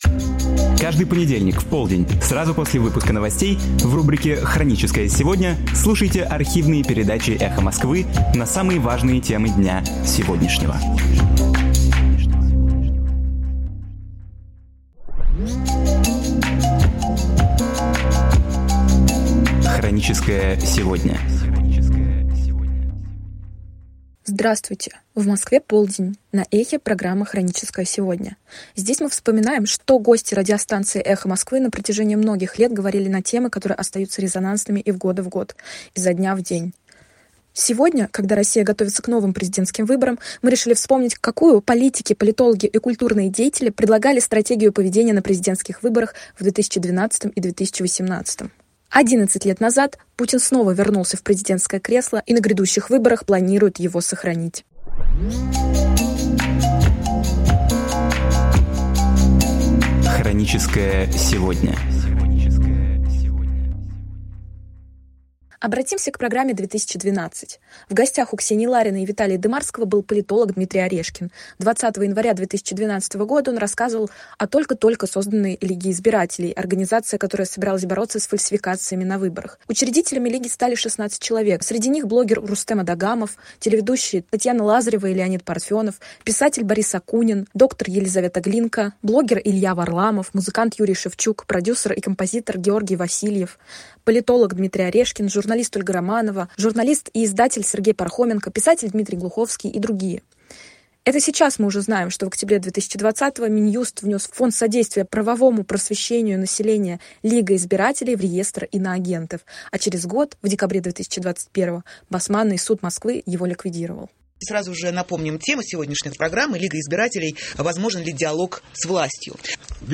Архивные передачи «Эха Москвы» с участием Дмитрия Орешкина, Бориса Немцова, Станислава Белковского, Сергея Шаргунова, Георгия Сатарова и Дмитрия Глуховского.
Программы из архива «Эха Москвы»